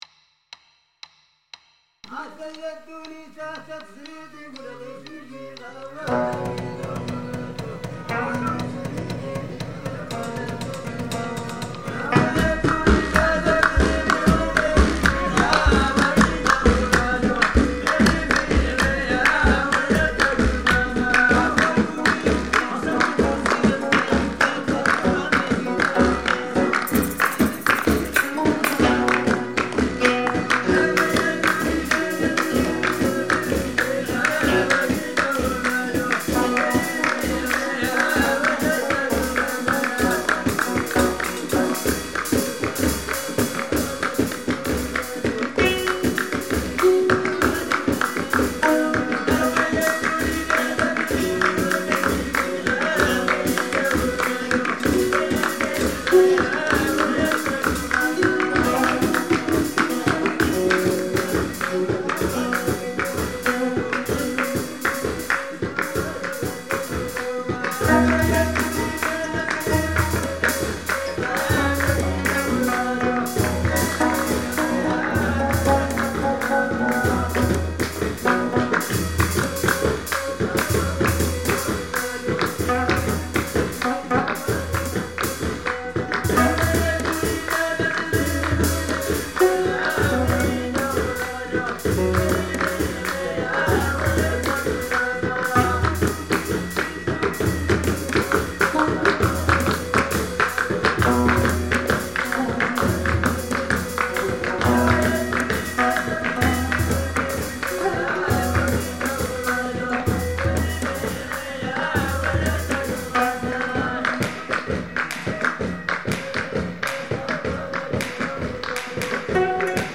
traditional kkwaenggwari rhythms were incorporated